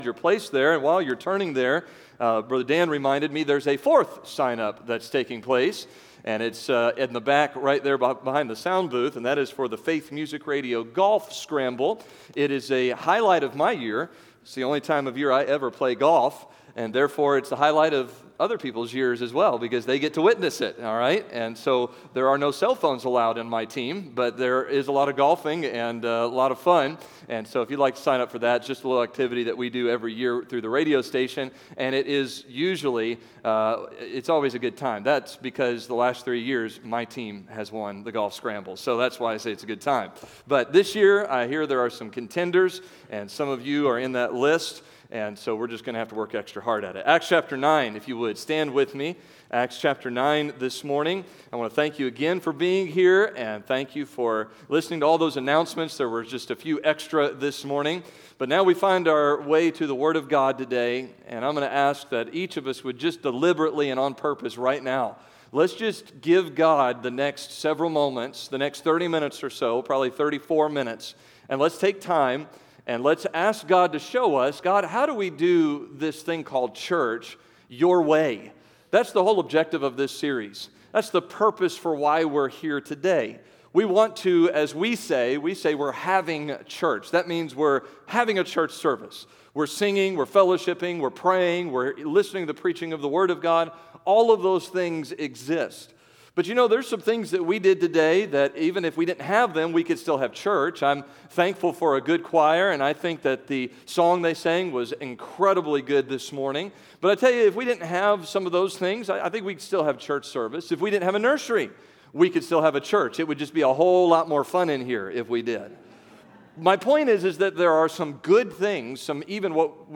August 2022 Sunday Morning Scripture: Acts 9:1-29 Download: Audio Leave A Comment Cancel reply Comment Save my name, email, and website in this browser for the next time I comment.